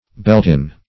Beltin \Bel"tin\, n.